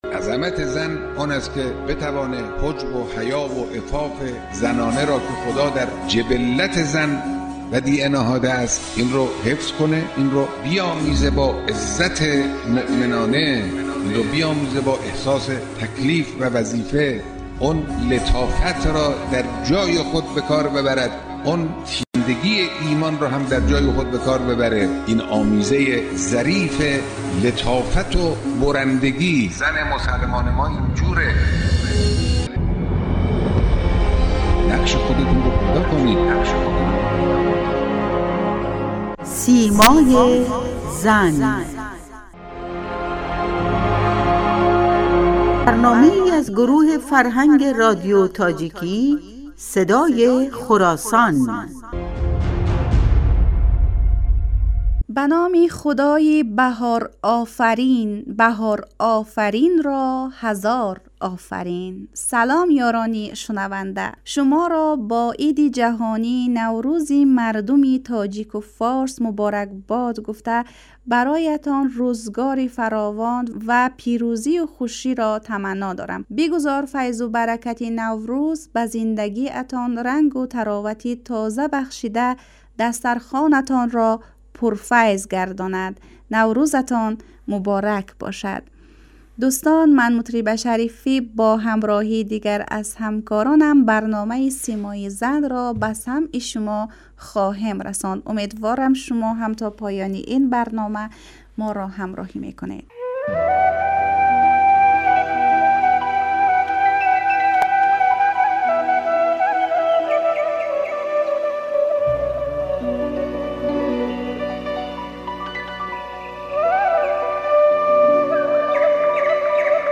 "سیمای زن" با هدف تبیین جایگاه زن در اسلام در رادیو تاجیکی صدای خراسان تهیه می شود.